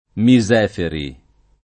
[ mi @$ feri ]